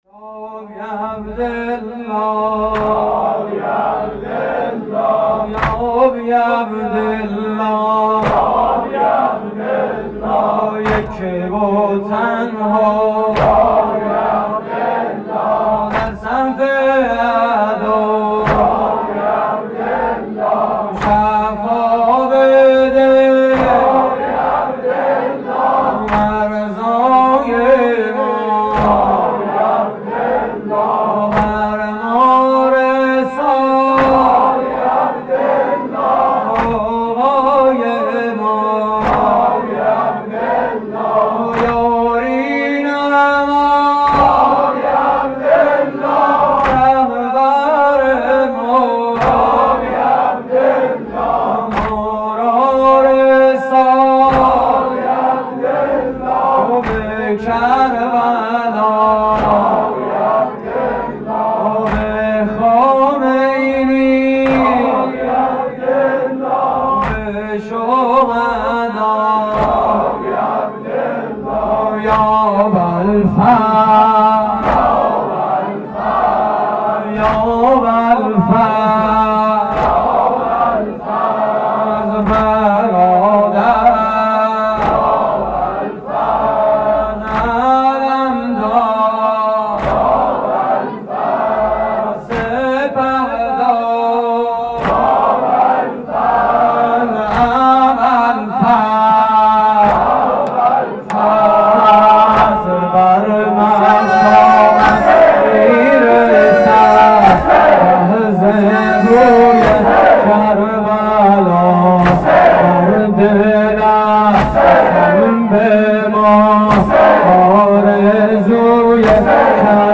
واحد: مظلوم حسین
مراسم عزاداری شب ششم محرم 1432